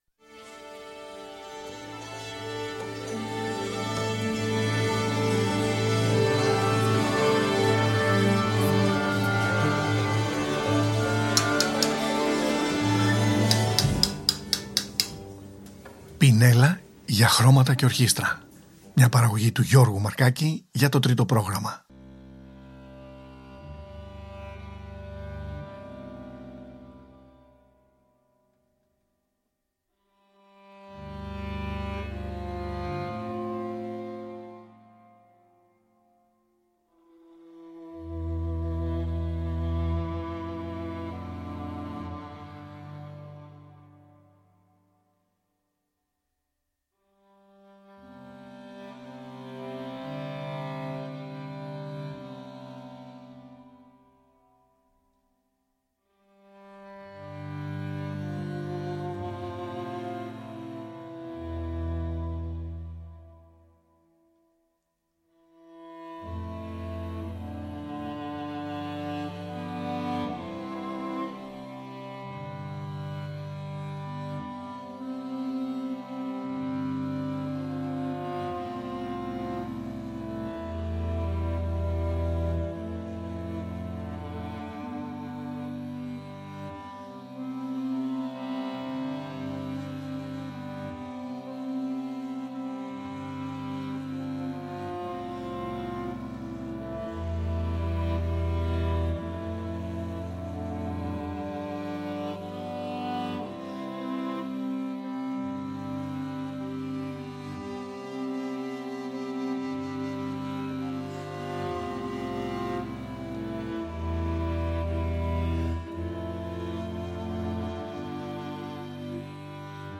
Ελεύθερη απόδοση σε ένα από τα πιο γνωστά δημώδη άσματα της Γεωργίας. Το “Tsintskaro”, ένα λαϊκό τραγούδι που πέρασε από τα χείλη μιας ανδρικής χορωδίας παραδοσιακών τραγουδιών της χώρας σε ενδιαφέρουσες ερμηνείες από γυναικείες φωνές.